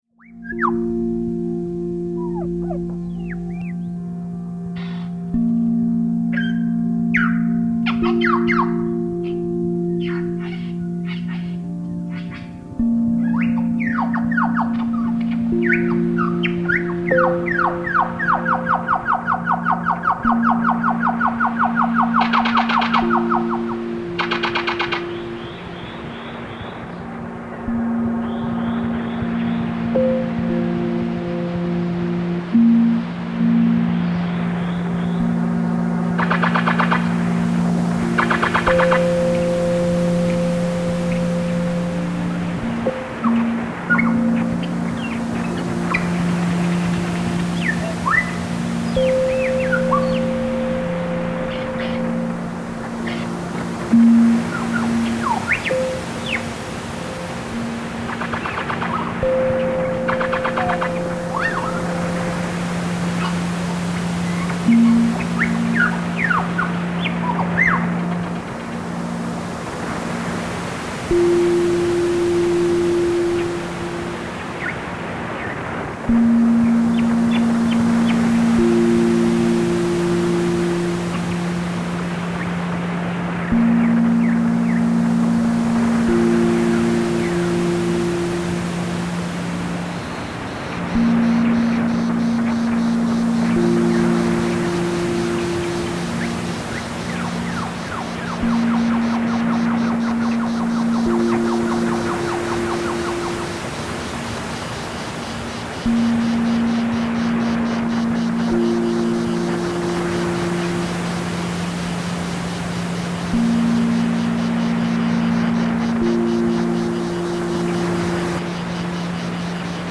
Dans la forêt, la biodiversité s’écoute plus qu’elle ne se voit
lossane tunnle forest.mp3